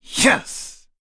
Zafir-Vox_Happy4.wav